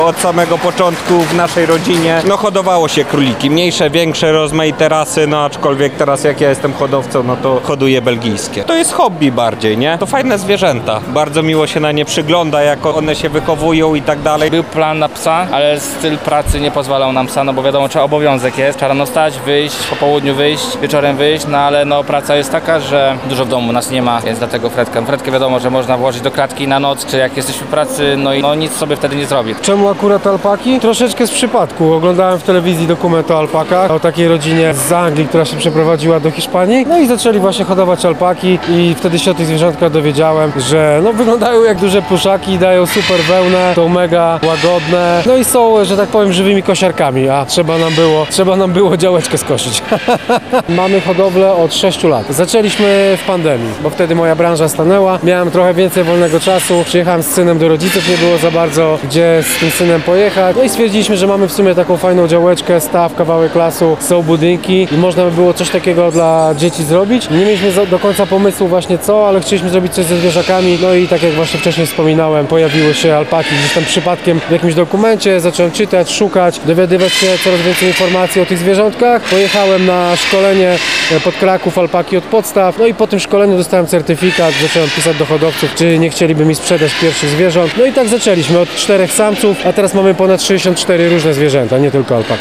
Za nami wiosenna edycja Lubelskiej Wystawy i Targów Zoologicznych ZOOPARK.
Wystawcy opowiedzieli nam, skąd bierze się pasja do wyjątkowych podopiecznych.